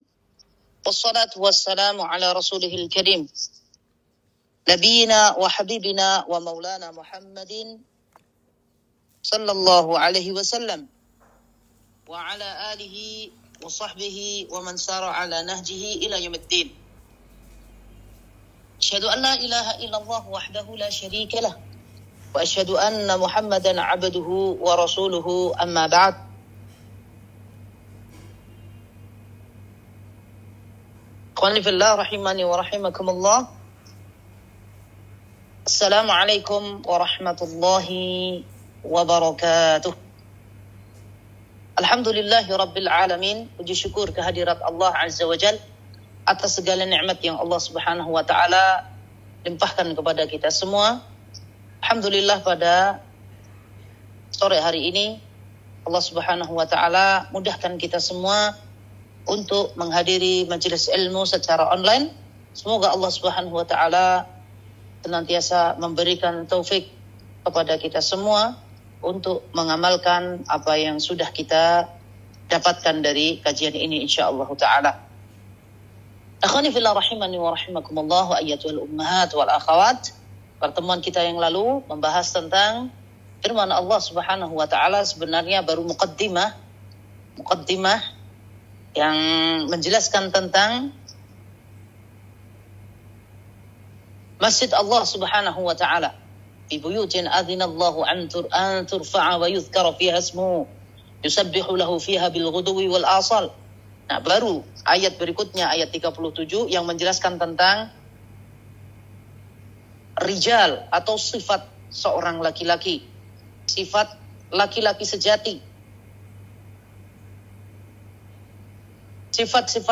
Kajian Online Shafar – Teams Awqaf Wakra, 11 Shafar 1445 / 27 Agustus 2023